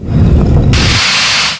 doorOpen.wav